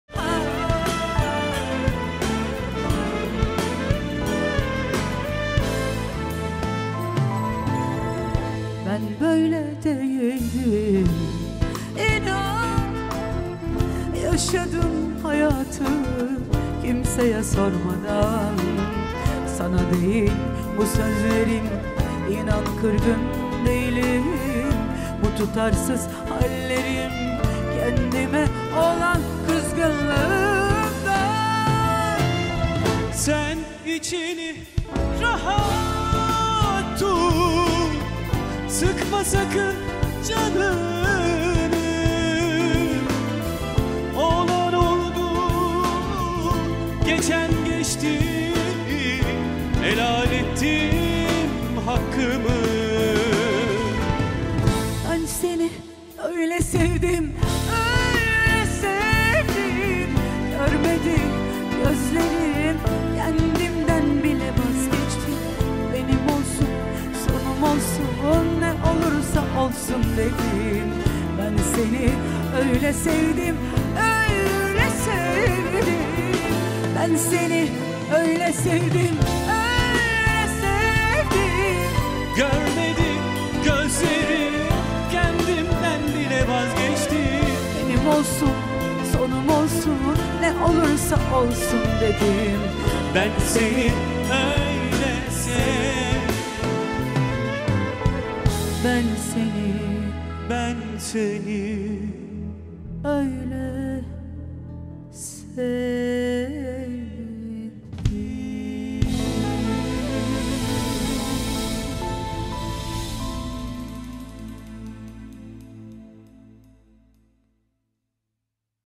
Akustik